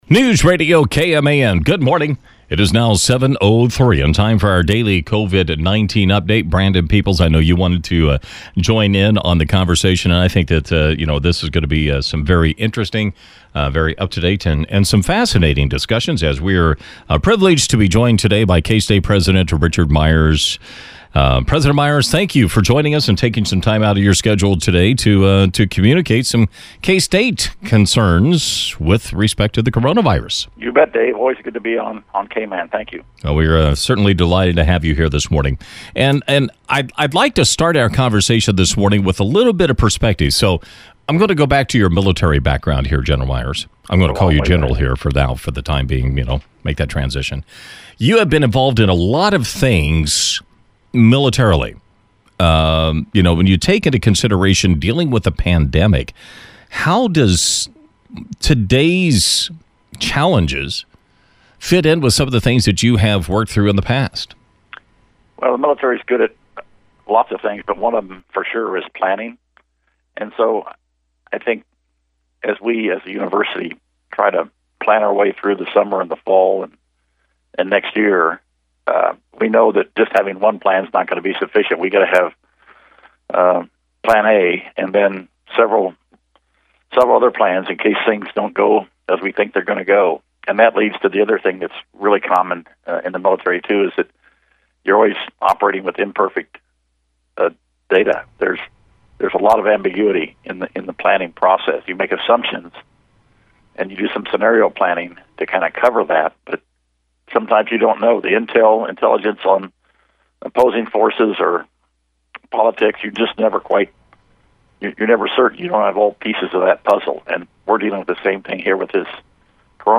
K-State President Richard Myers joined KMAN in the 7 AM hour to provide our daily COVID-19 update.